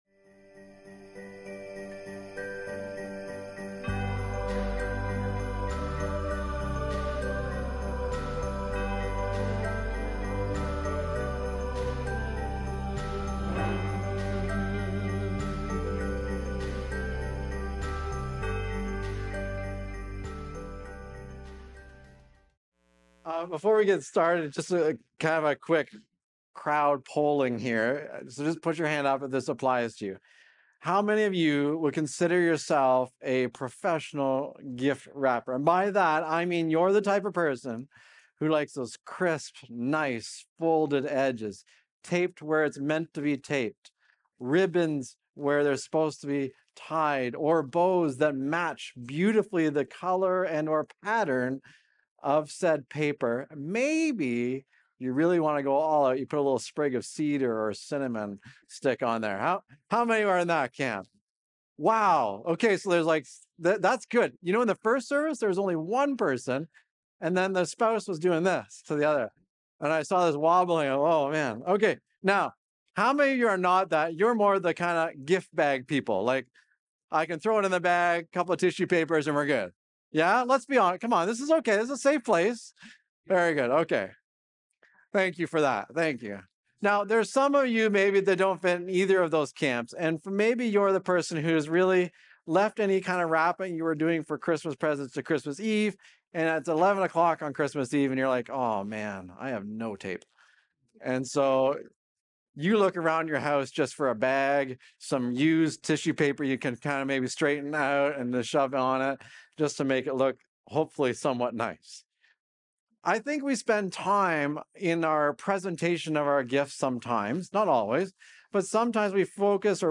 Recorded Sunday, December 21, 2025, at Trentside Fenelon Falls.